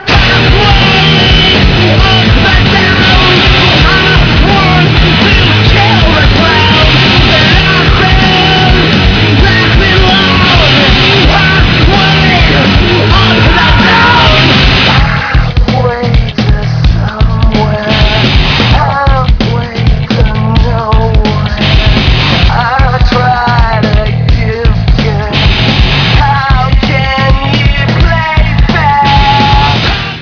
Vocals and Bass
Guitar
Drums